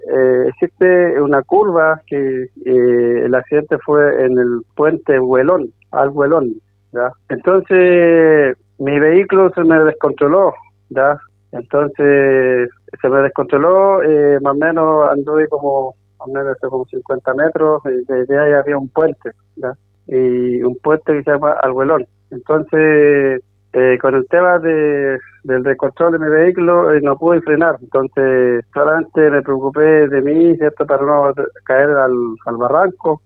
En diálogo con Radio Bío Bío, Tranamil señaló que perdió el control de la camioneta cuando enfrentó una curva e ingresó al viaducto.